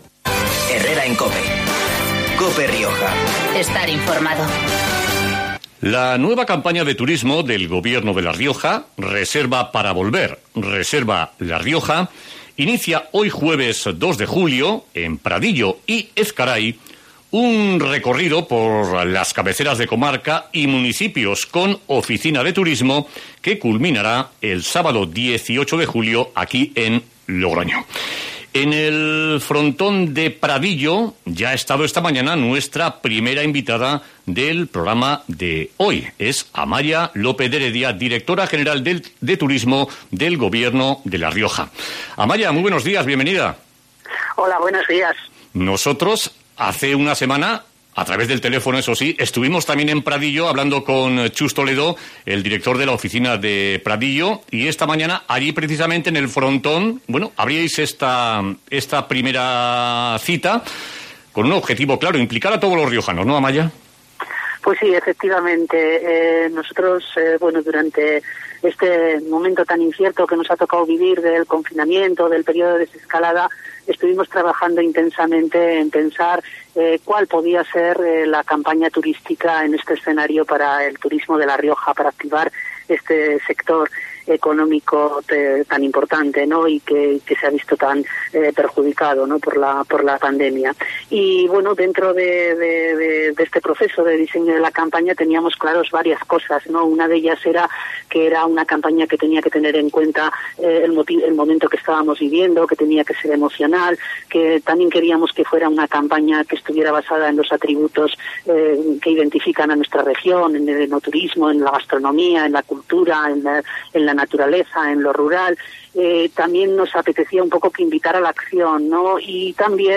Entrevista a Amaia López de Heredia, directora general de Turismo, en "Herrera en COPE Rioja"